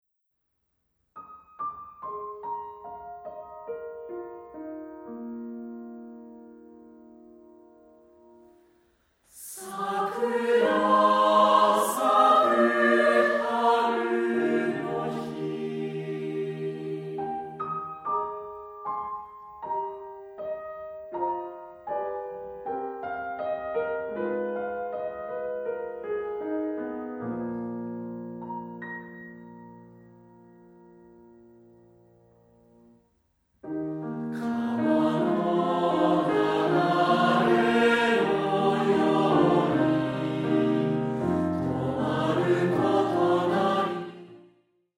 混声4部合唱／伴奏：ピアノ